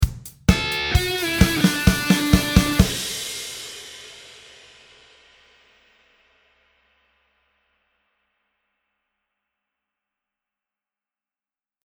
Play, download and share Smaller Riff original sound button!!!!
smaller-riff.mp3